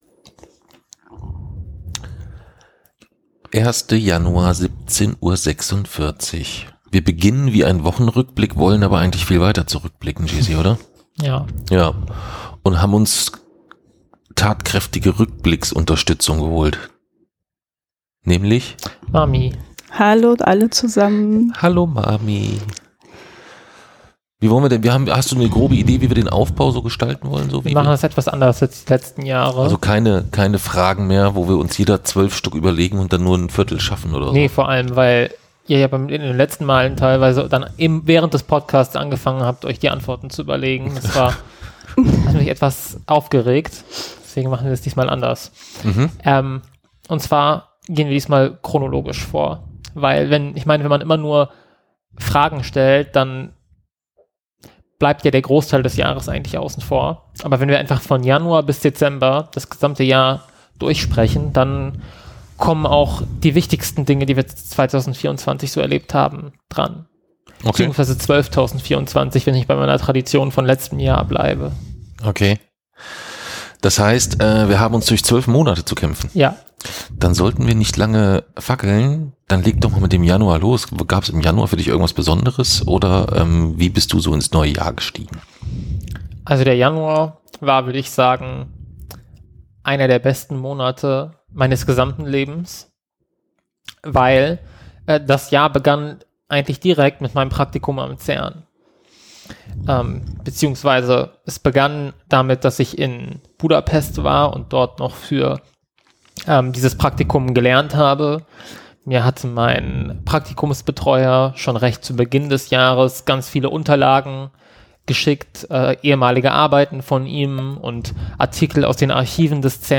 Da jagt mal wieder ein Lacher den nächsten.